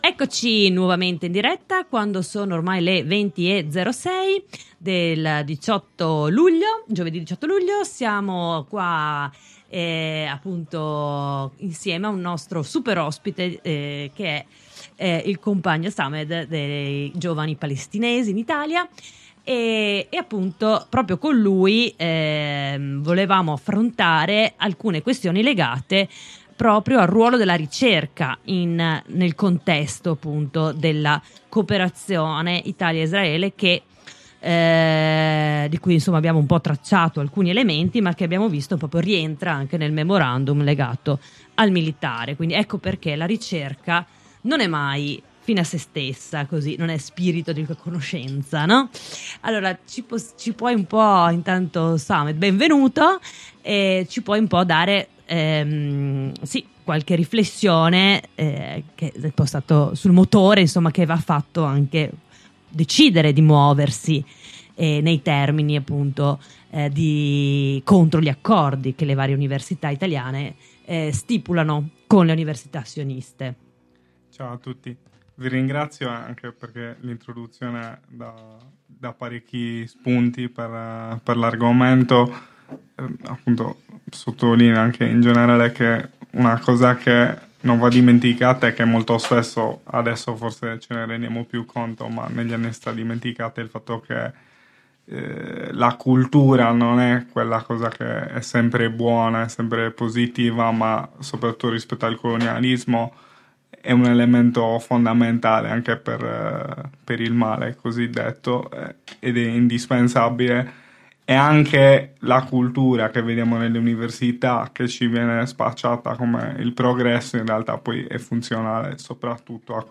Intervista ai GPI sulle lotte in solidarietà alla Resistenza palestinese